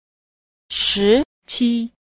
4. 時期 – shíqí – thời kỳ